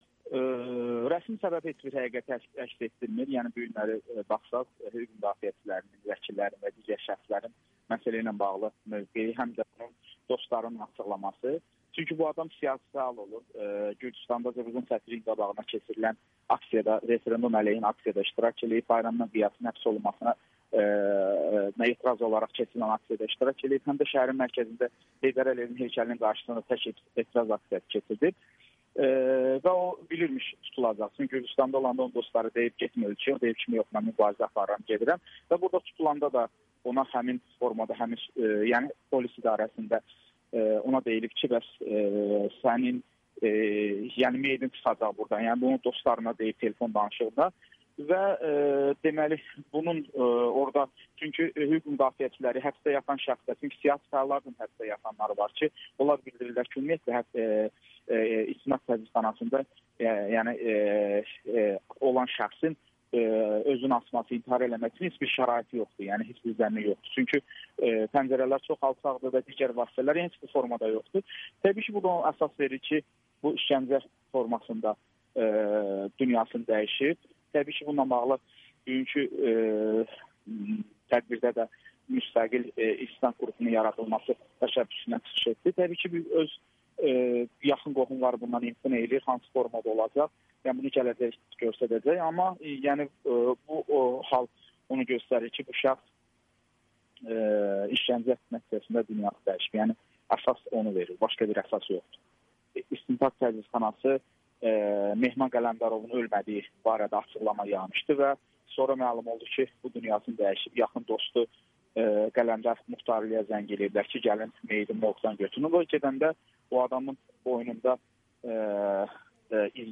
Siyasi fəallara qarşı qəddar münasibət nədən irəli gəlir? [Audio-Müsahibələr]